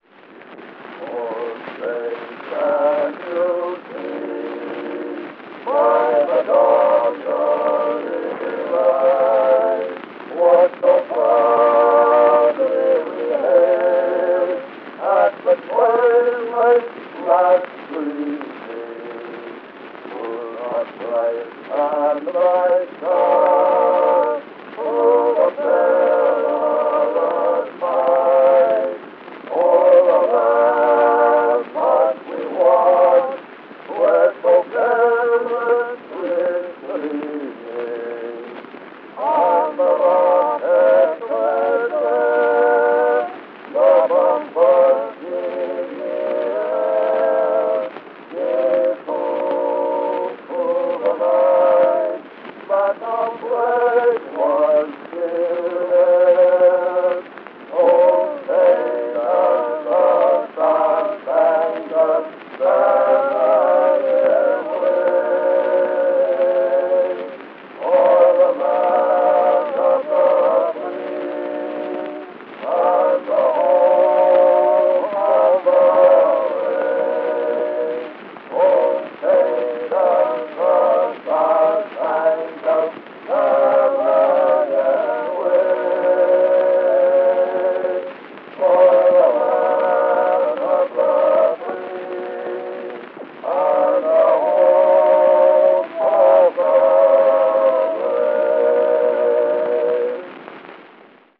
The Star Spangled Banner, The Diamond Four (ca. 1898) Berliner 4258, 7-inch 70 rpm record found here.